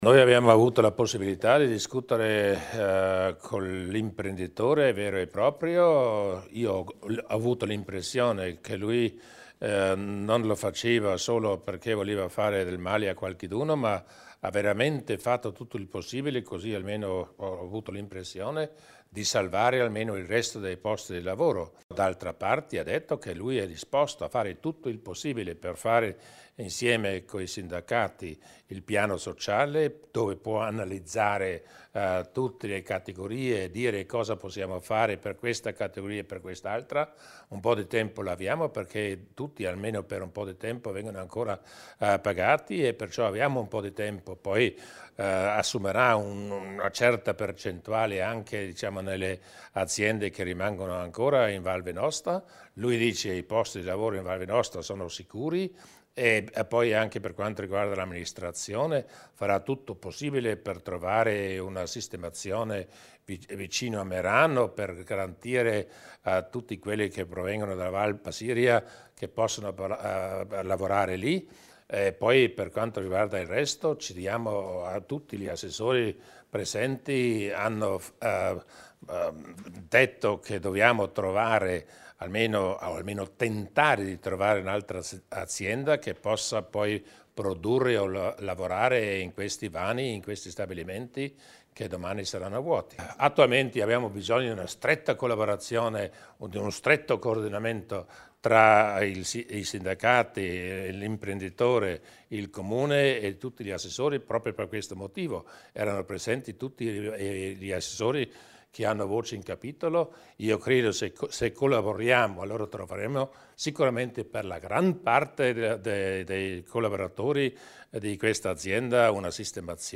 Il Presidente Durnwalder illustra le decisioni per dare sostegno ai lavoratori della Hoppe